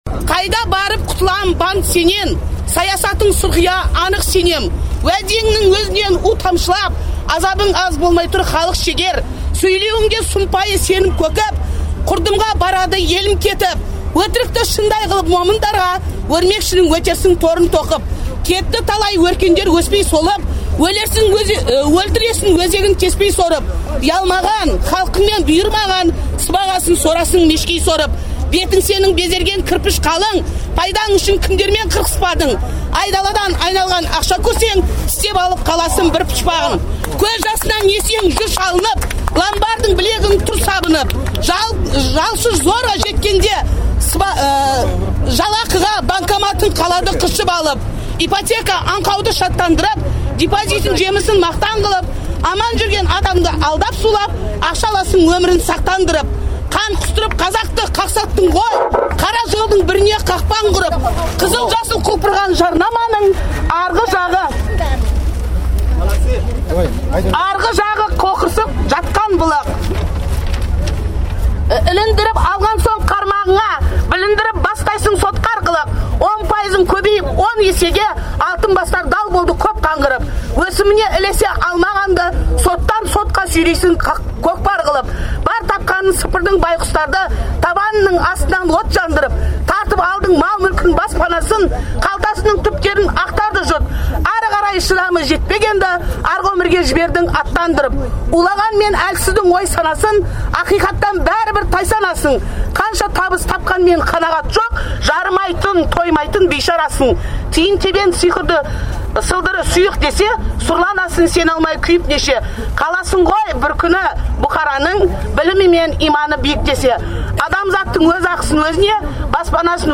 Қарсылық жиыны. Алматы, 28 сәуір 2012 жыл.